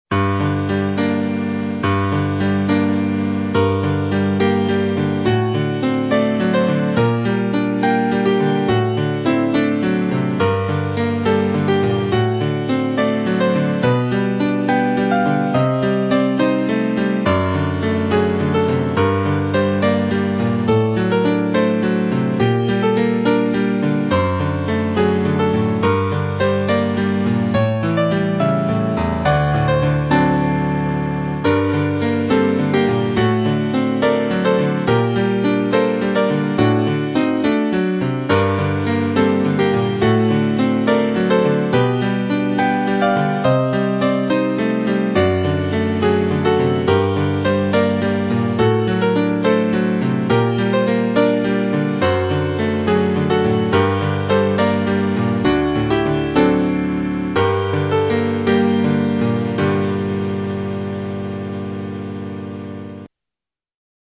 Erinnerung an Wien, for piano, in G minor
The piece is mostly based on the theme of Schubert's most popular Marche Militaire and somehow pastiches his piano and lied music.